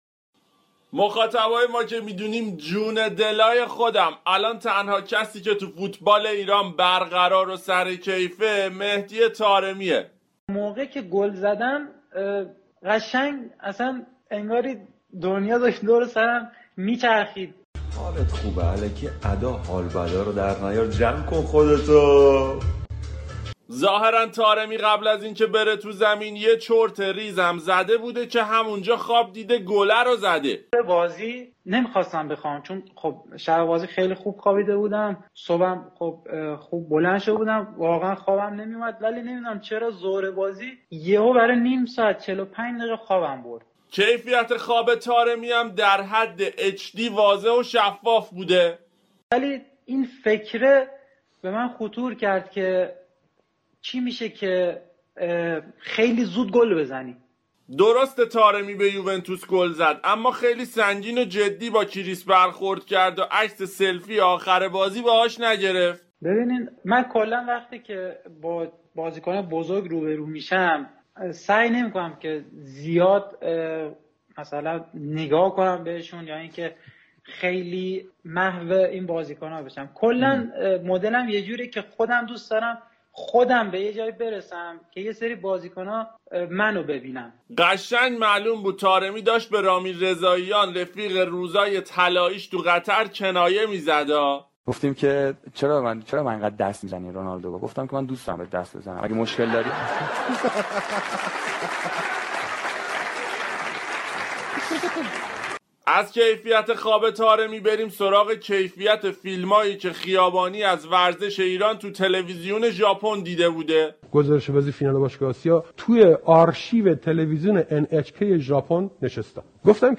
آیتم طنز